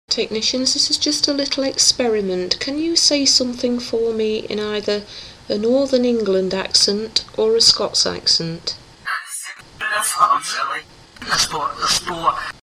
another accent on request, this time, Geordie – The Amazing Portal
Here is another experiment where we ask our Communicators to try to respond with an accent. This also shows their sense of humour as they respond that ‘they will sound silly’ they then say ‘Let’s go to the store’ in a perfect Geordie accent!